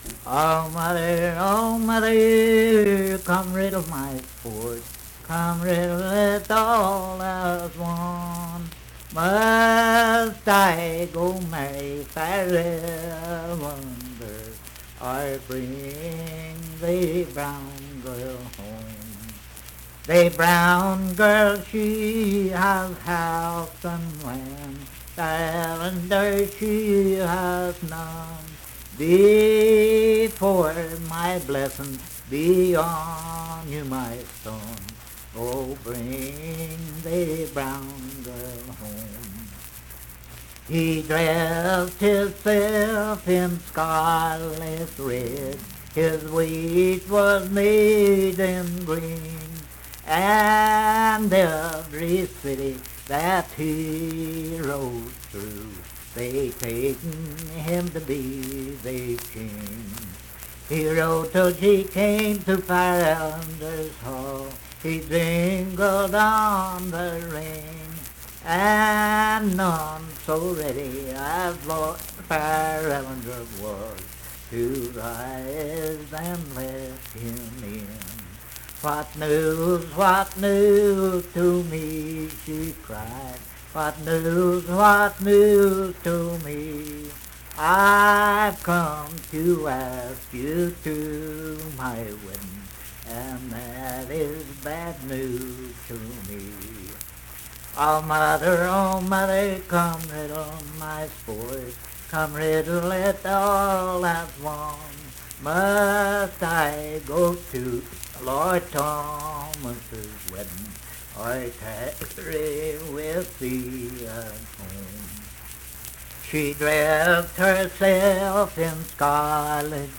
Unaccompanied vocal music
Verse-refrain 15(4).
Voice (sung)